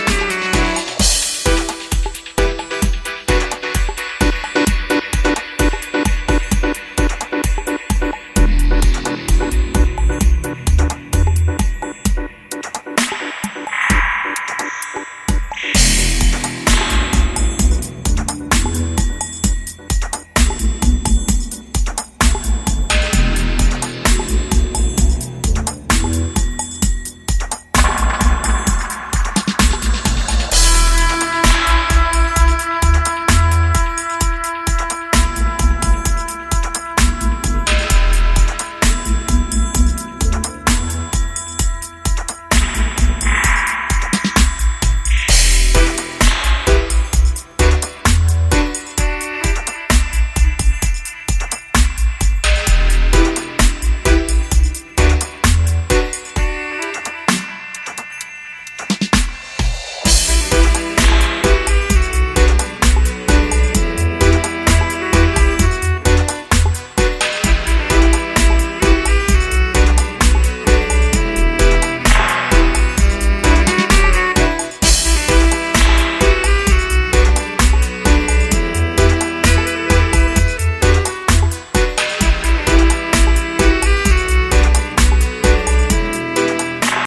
180 gram vinyl with some deep dub Reggae inspired tracks.
Dub Reggae Bass